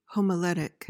PRONUNCIATION:
(hom-uh-LET-ik)
homiletic.mp3